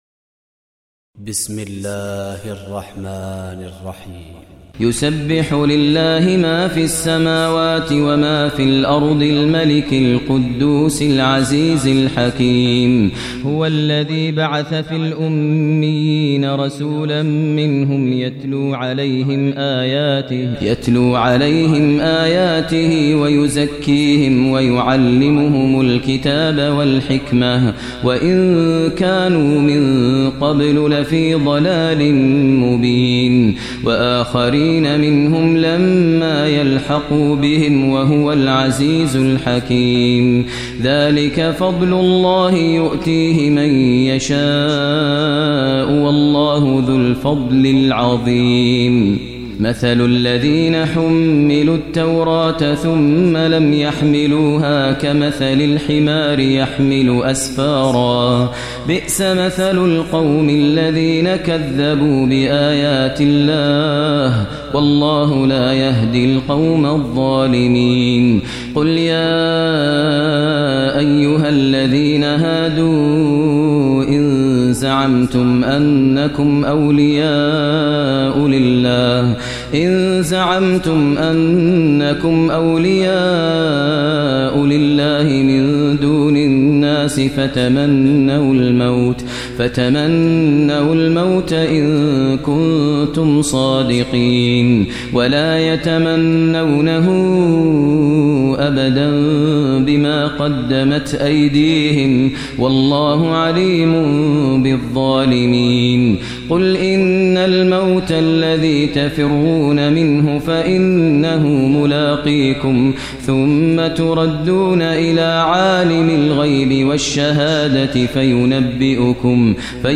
القران الكريم بصوت القارى ماهر المعيقلي كامل